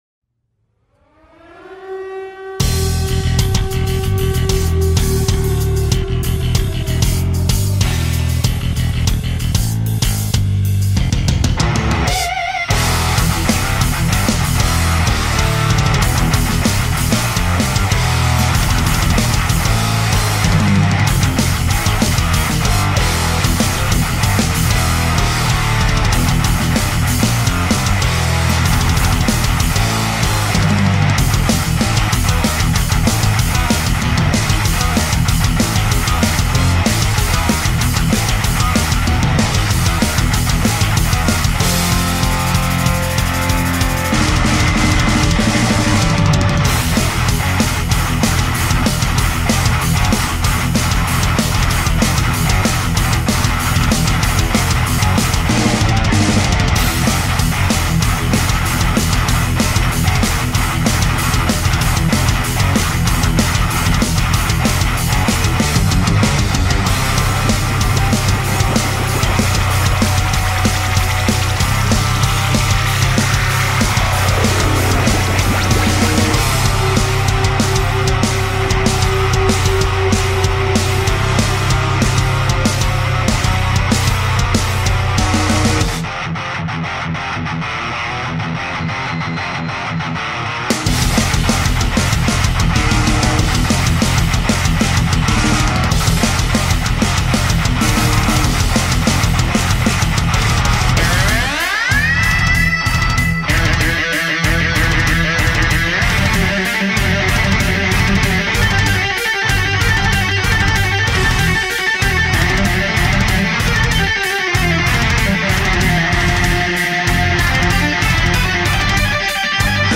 An Instrumental Progressive Thrash Metal song!